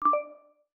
Simple Cute Alert 23.wav